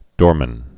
(dôrmĭn)